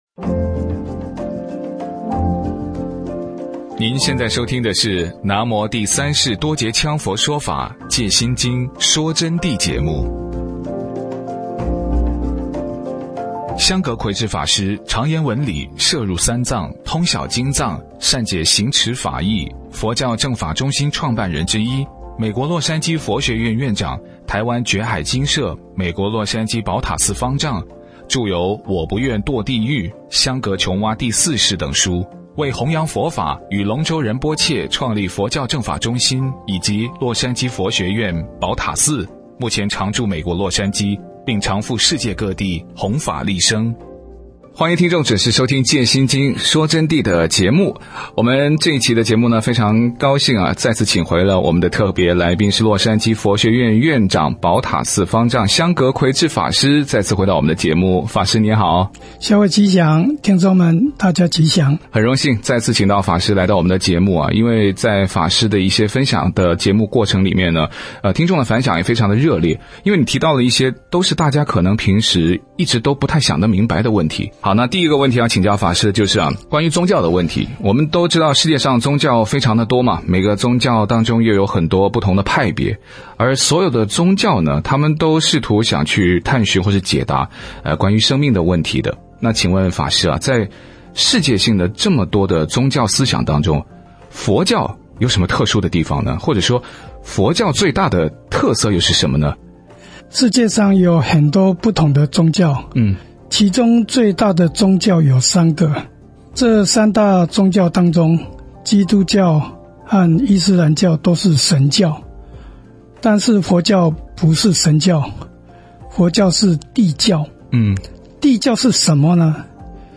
佛弟子访谈（二十三）为什么佛教是圣教？